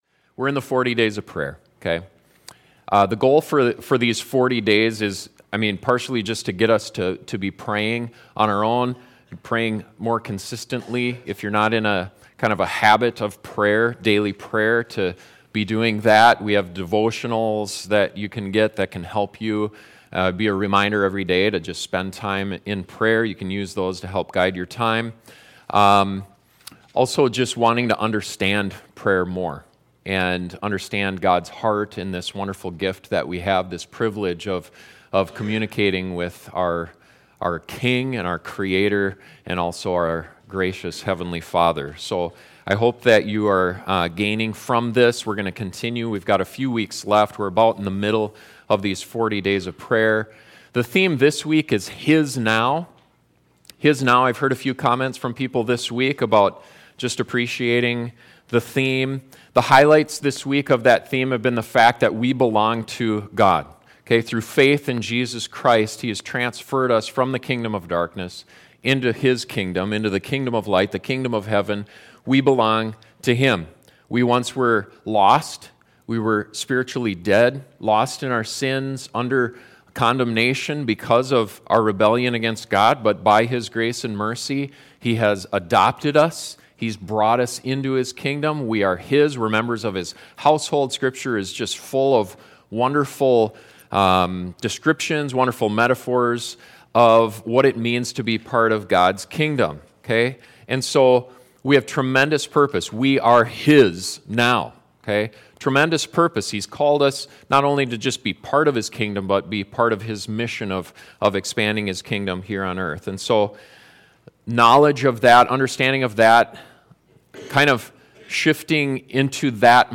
This identity influences every part of our lives, including how we pray and this sermon will stir up our thinking about our approach to prayer.